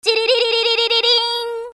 携帯電話用音声集
携帯電話の着信音声として使える音声25個セットです。